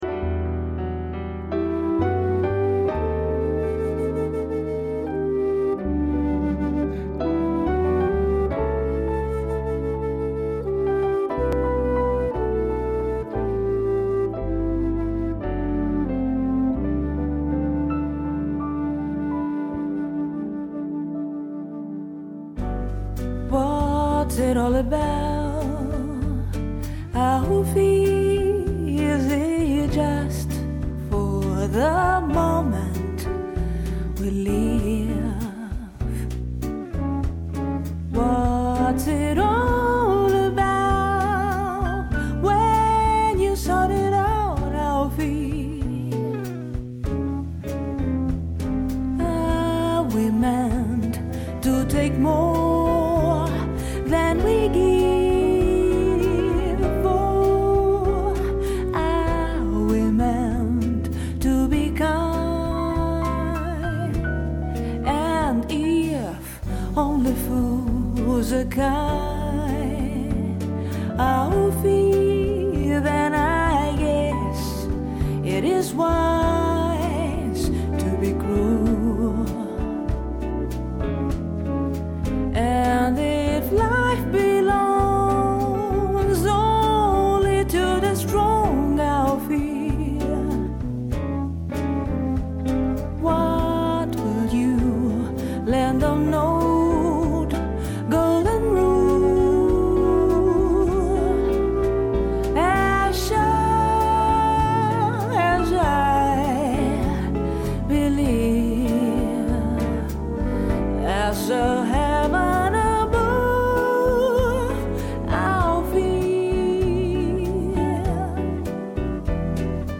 Вот такая песня
а кто поет не знаю